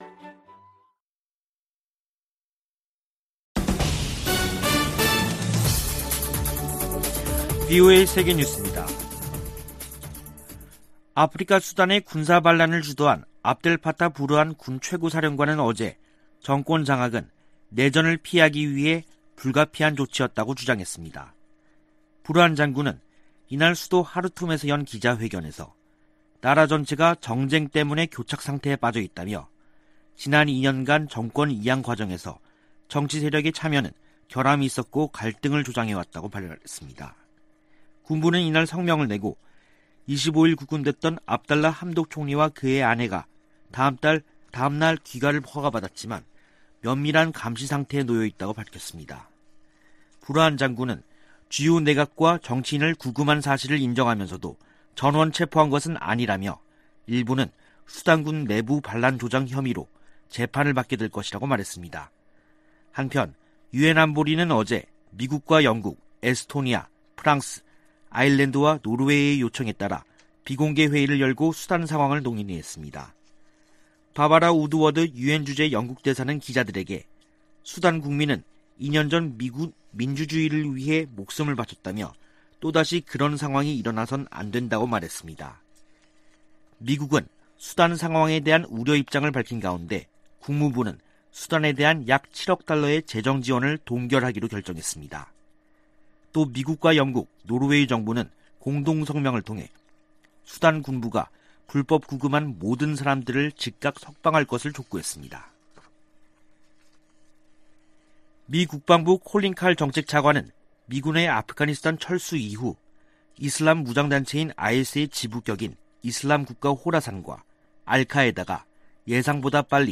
VOA 한국어 간판 뉴스 프로그램 '뉴스 투데이', 2021년 10월 27일 2부 방송입니다. 대북 접근법 수행 과정에 미국과 한국의 관점이 다를 수도 있다고 제이크 설리번 미 국가안보 보좌관이 밝혔습니다. 미 국무부가 국제 해킹 대응 조직을 신설합니다. 캐나다 인권단체가 한국과 미국에 이어 세 번째로 제3국 내 탈북 난민 수용 시범 프로그램을 시작합니다.